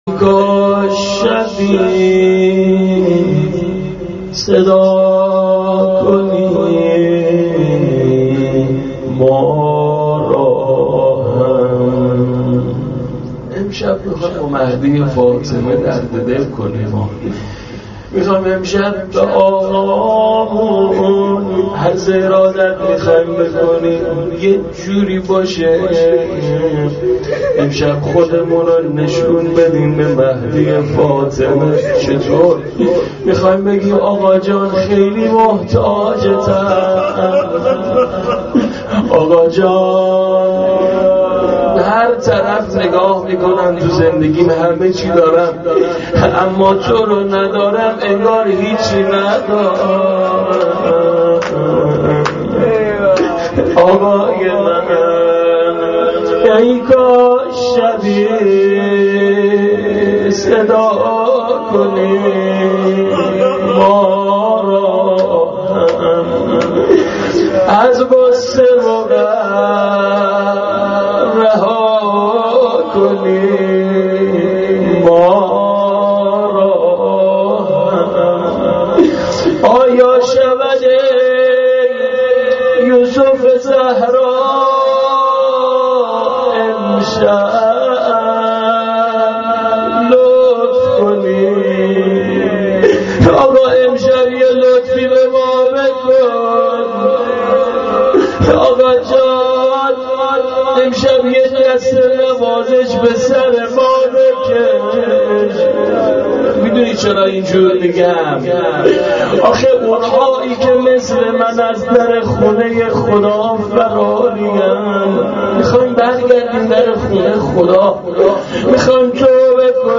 مناجات با امام زمان ای کاش شبی صدا کنی ما را هم.MP3